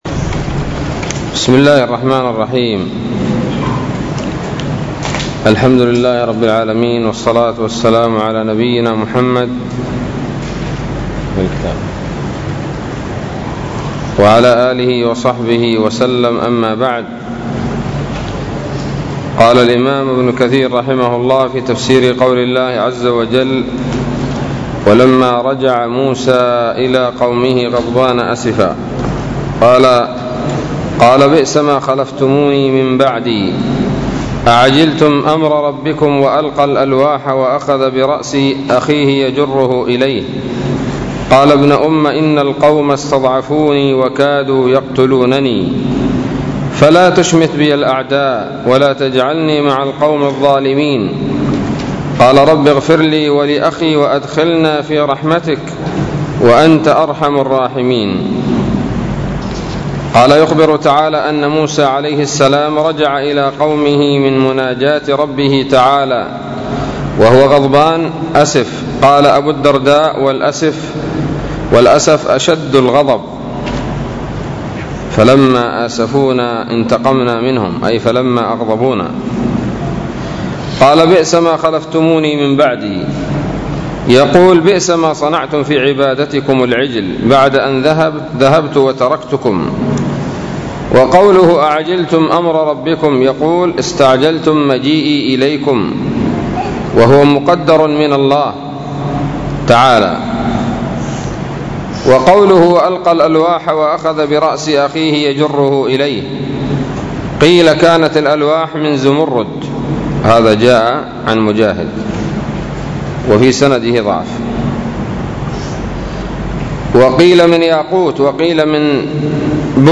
الدرس السادس والأربعون من سورة الأعراف من تفسير ابن كثير رحمه الله تعالى